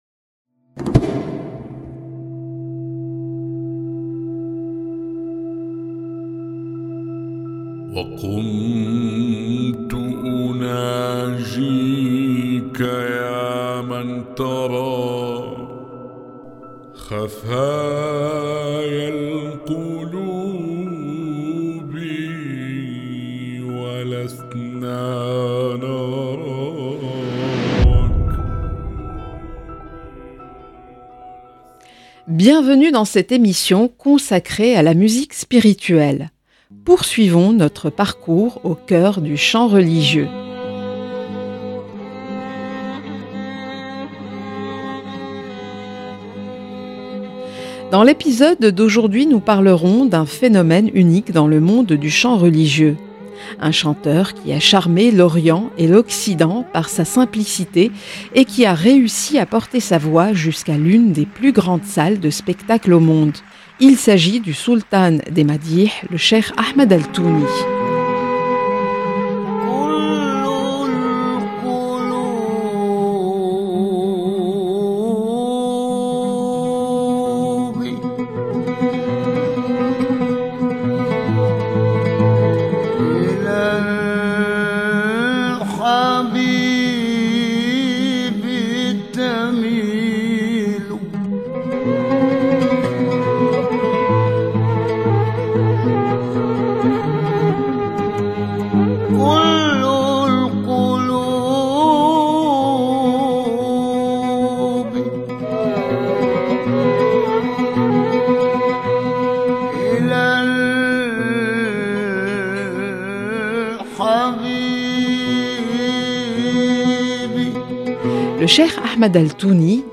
une émission sur la musique spirituelle